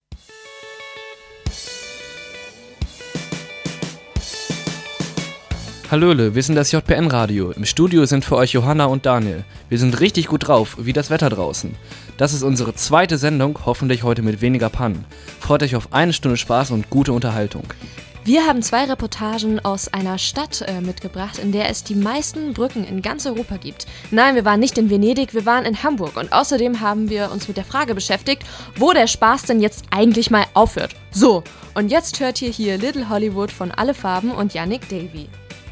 Anmoderation
Anmoderation.mp3